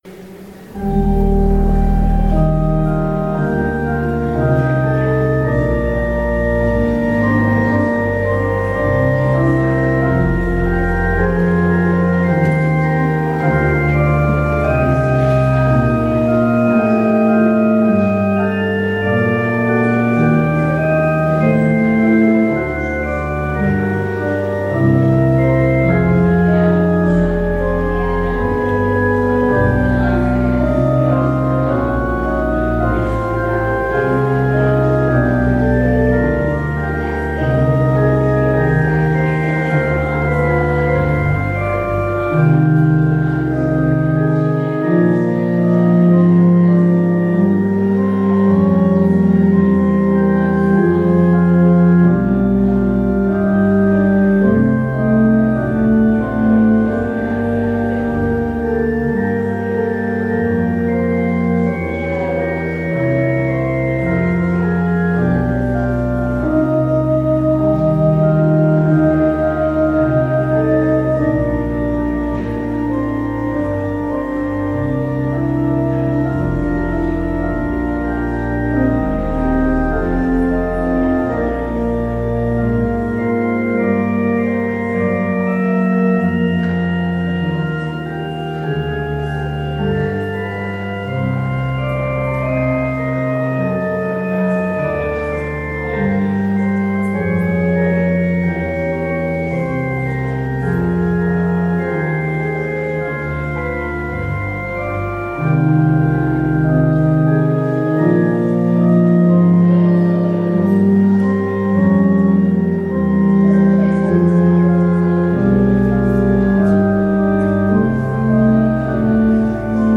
Audio recording of the 10am service
Due to technical problems, there was no available recording of the service from the eagle and clergy mikes, so the entire service was recorded as ambient sound.